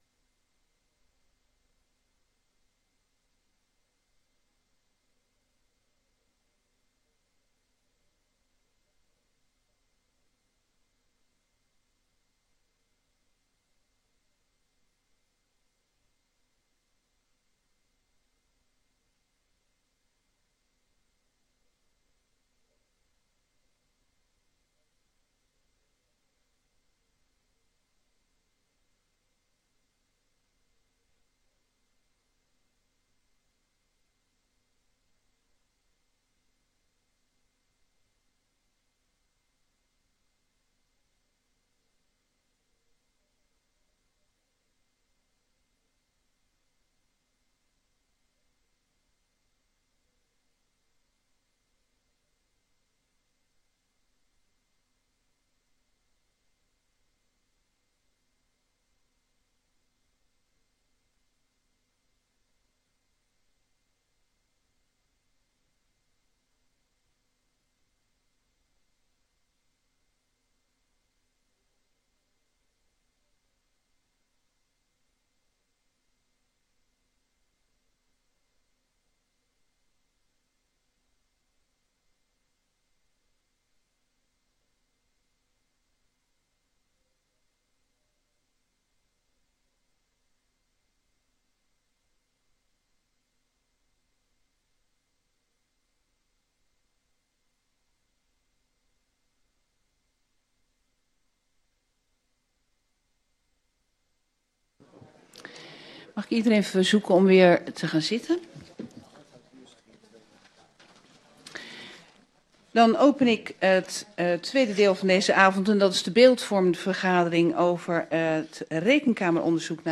Beeldvormende bijeenkomst Papendrecht 18 september 2025 20:30:00, Gemeente Papendrecht
Locatie: Raadzaal Voorzitter: Trijntje van Es